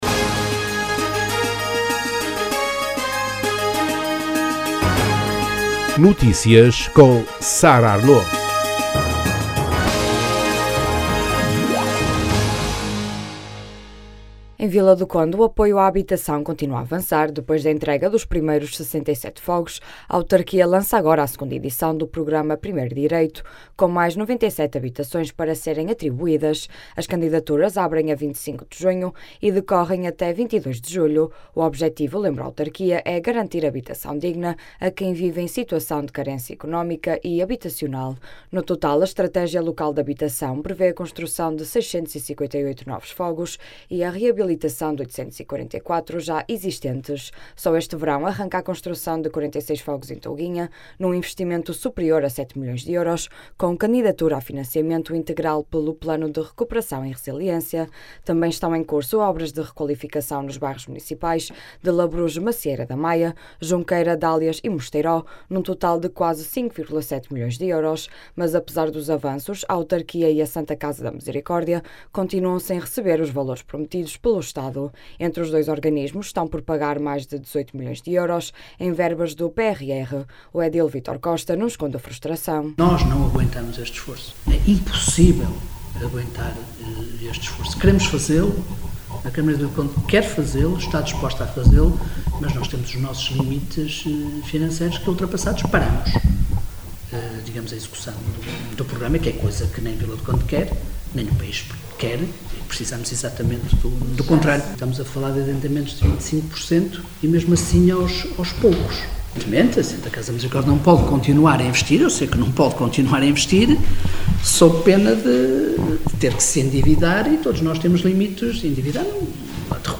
Notícias Regionais